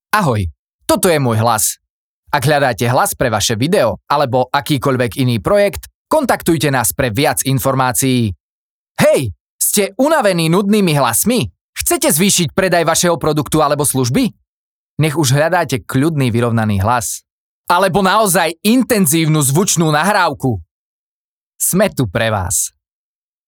Hlas do reklamy (voiceover)
(vyčistenie nahrávky od nádychov rôznych ruchov a zvuková postprodukcia je samozrejmosťou)